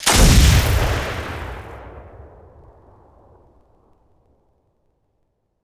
sniper2.wav